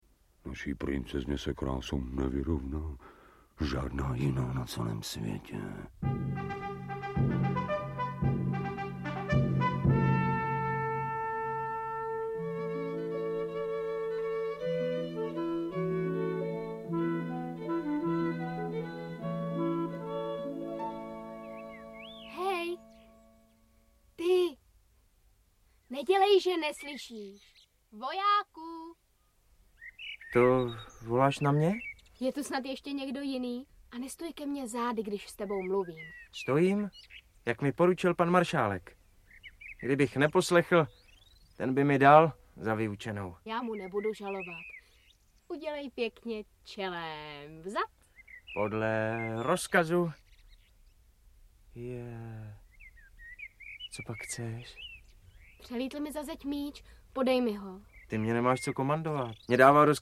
Audiobook
Read: Eliška Balzerová